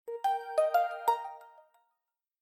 Звуки телефона Lenovo